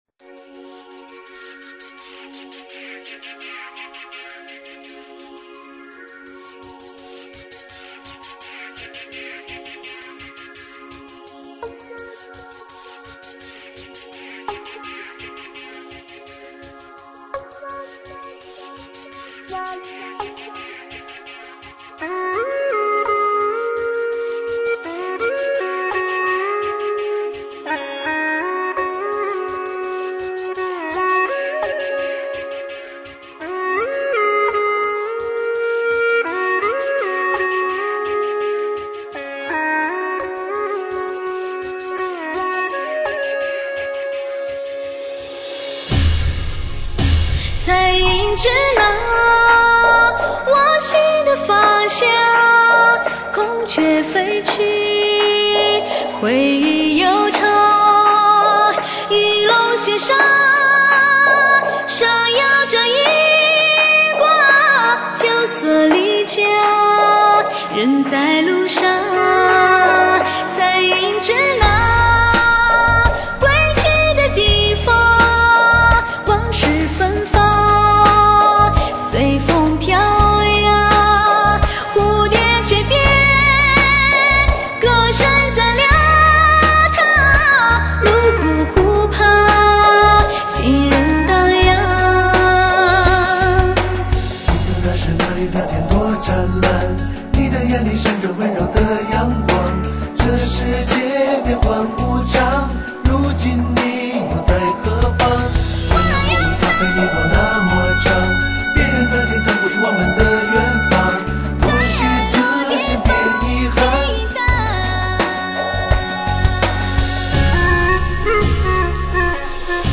现代发烧，声声入胃，多么动听的声音！